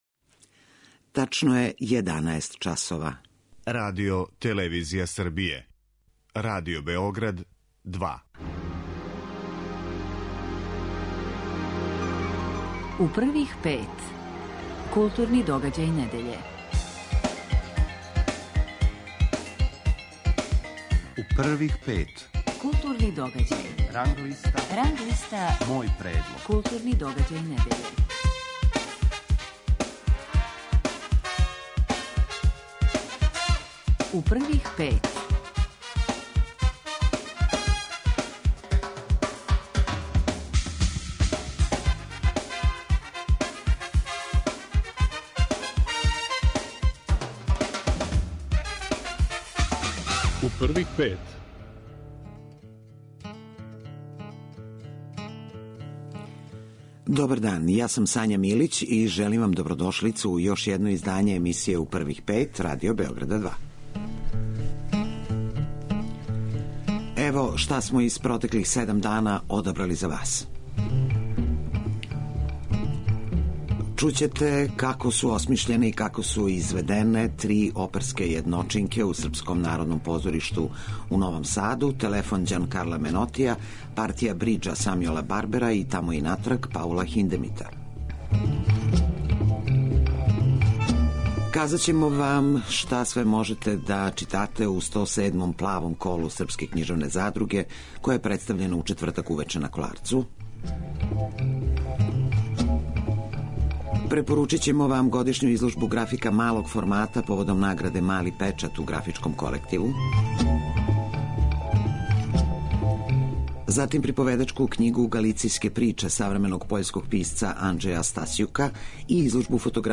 Гост емисије 'У првих пет' биће редитељ и сликар Слободан Шијан.